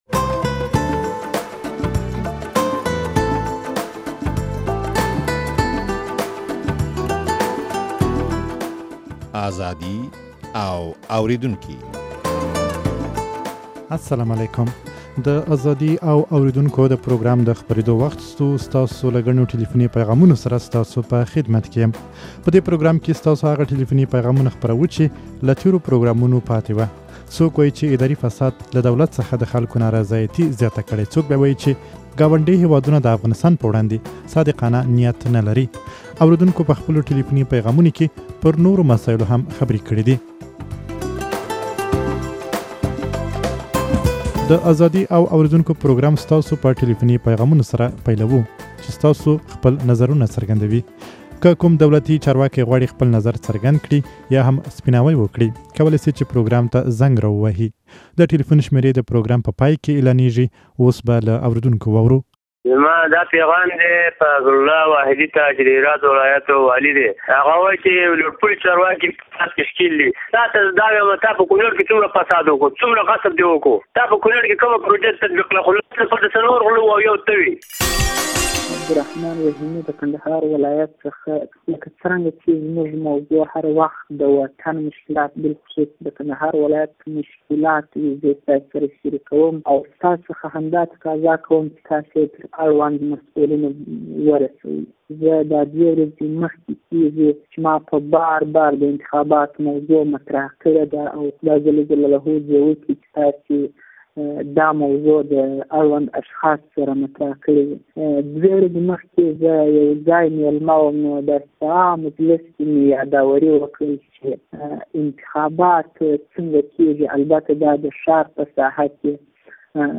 په دې پروګرام کې ستاسو هغه ټليفوني پيغامونه خپروو، چې له تېرو پروګرامونو پاتې وو، څوک وايي چې اداري فساد له دولت څخه د خلکو نارضايتي زياته کړې